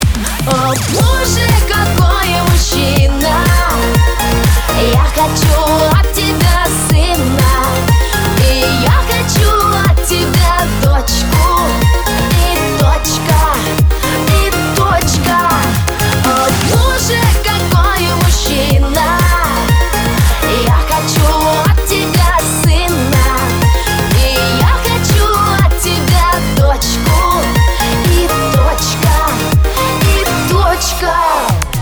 • Качество: 320, Stereo
поп
шлягер